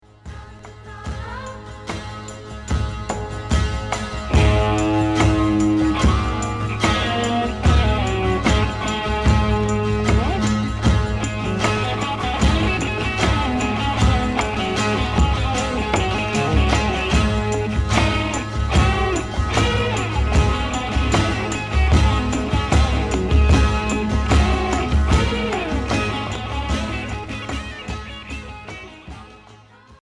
Pop psychédélique Unique 45t retour à l'accueil